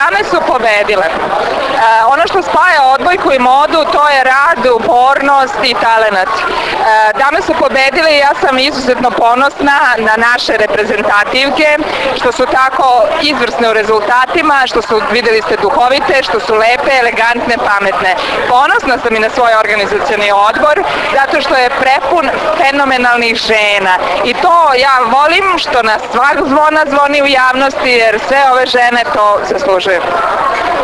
IZJAVA SNEŽANE SAMARDŽIĆ – MARKOVIĆ